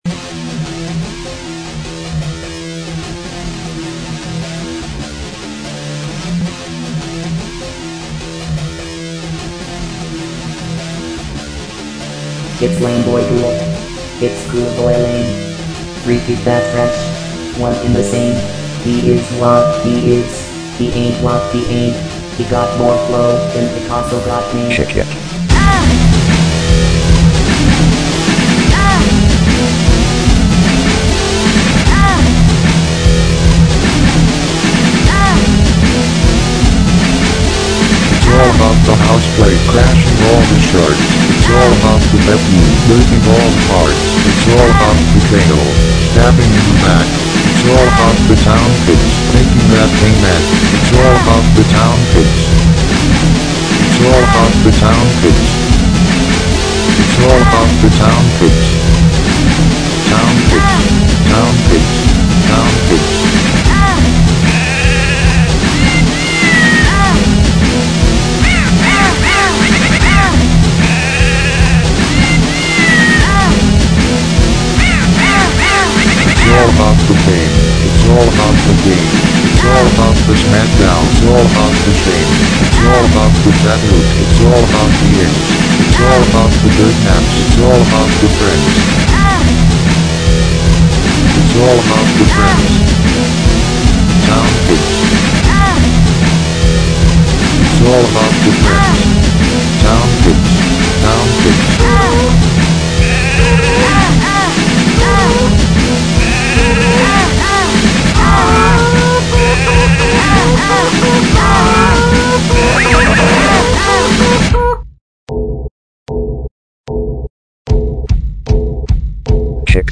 Another one I really liked, but the mix was pretty muddy.
I am pretty sure all the drums/percussion in this one are UO sounds.  It's still all about the town pimps.